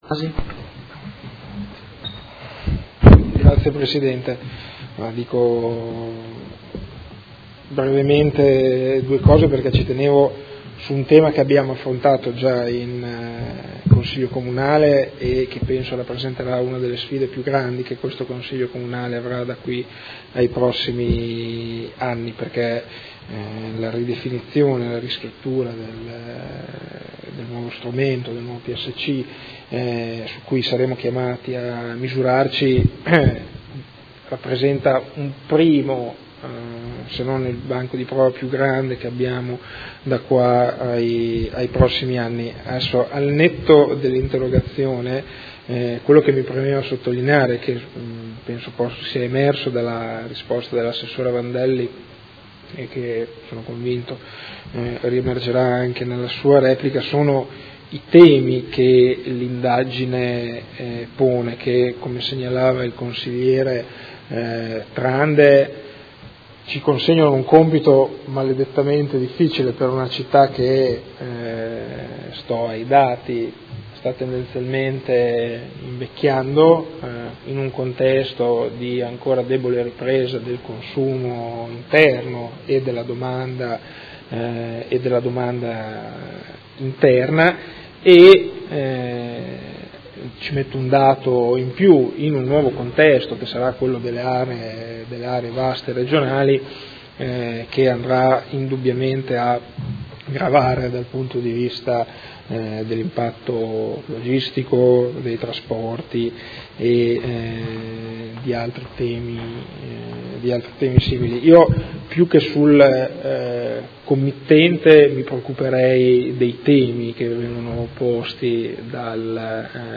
Seduta del 01/06/2017. Dibattito su interrogazione del Gruppo Movimento cinque Stelle avente per oggetto: Scenari demografici in vista del nuovo PSC-PUG